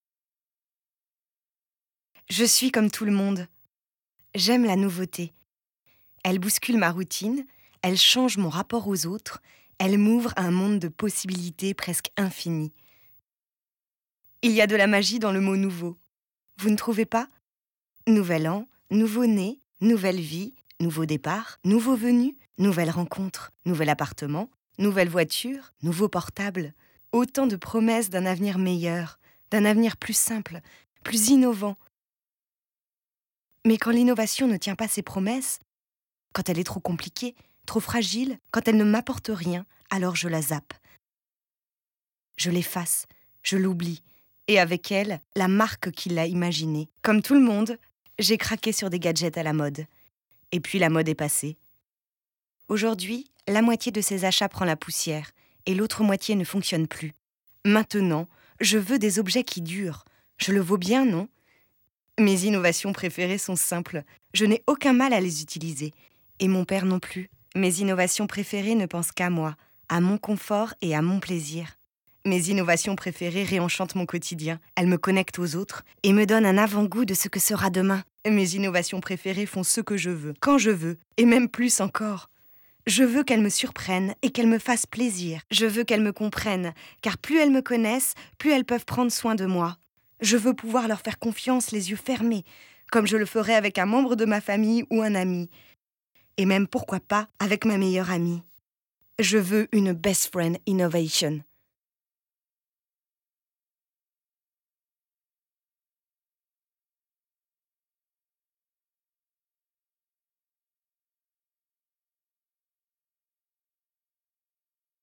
Voix off pour pub interne Citroën
- Mezzo-soprano